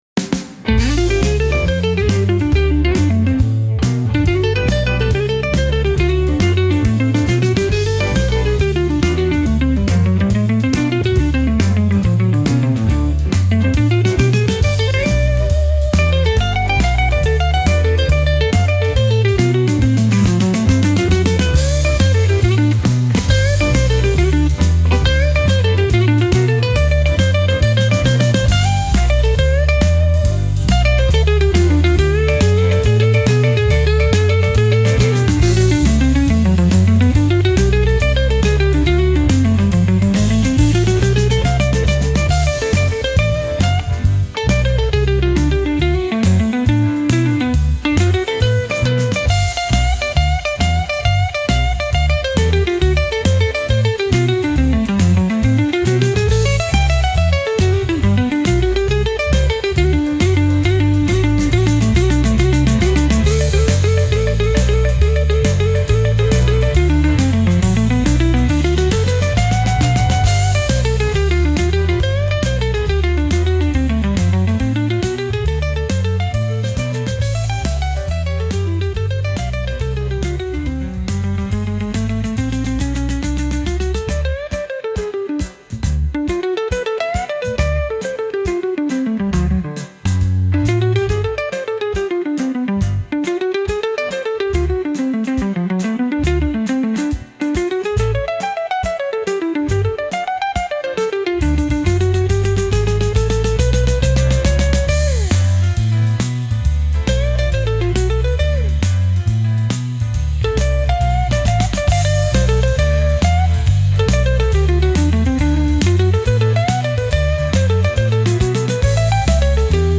Instrumental - RLMradio Dot XYZ - 2.14.mp3